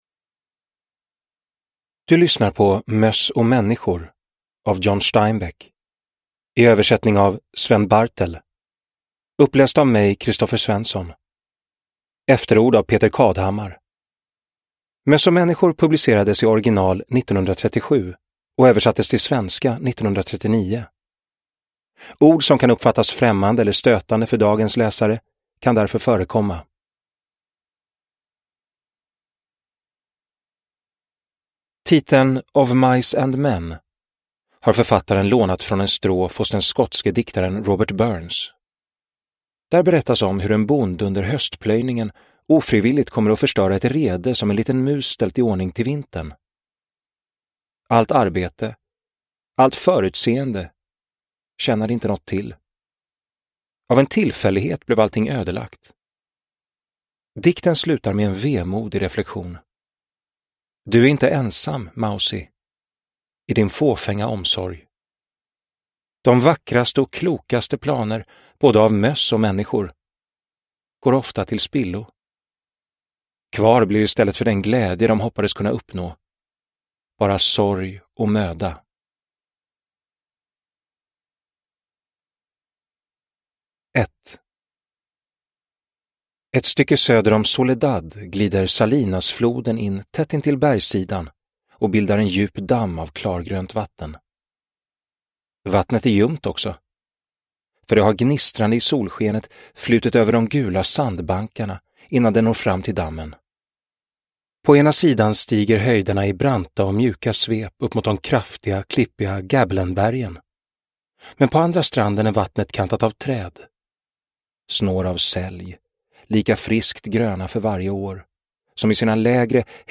Möss och människor – Ljudbok – Laddas ner